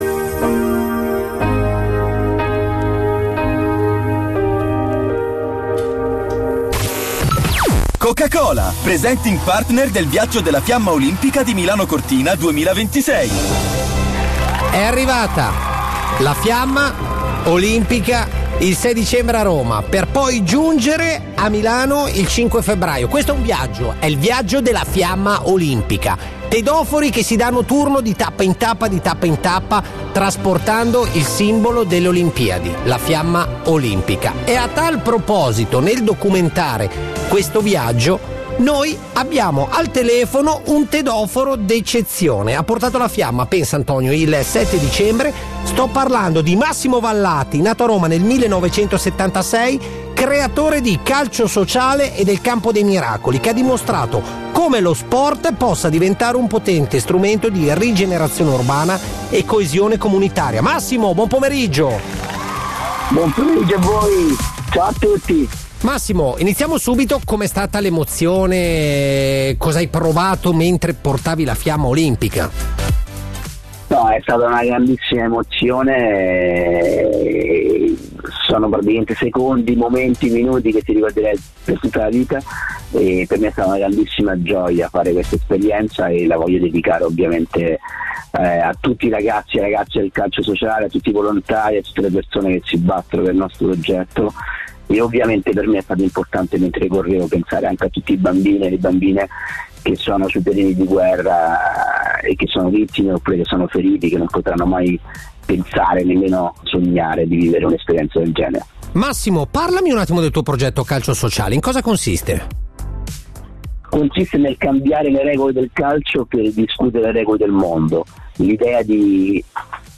Ecco la sua intervista a Radio 105: